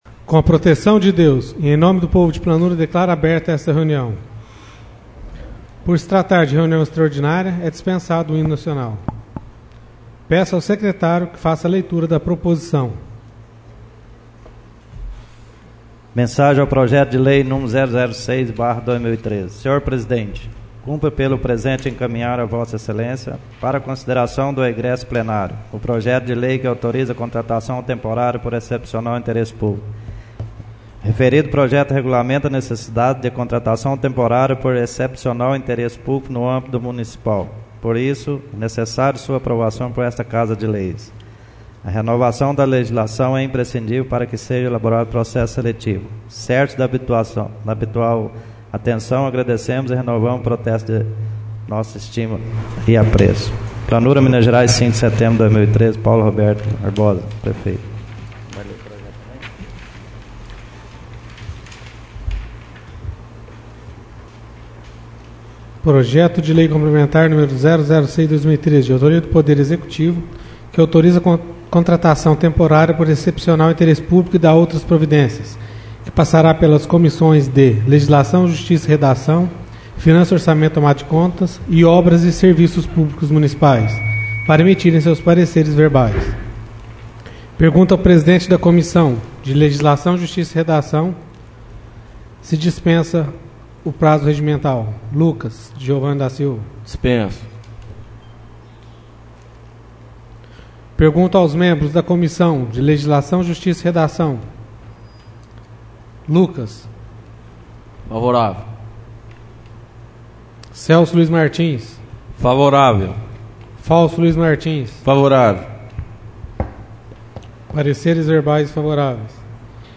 Sessão Extraordinária - 11/09/13 — CÂMARA MUNICIPAL DE PLANURA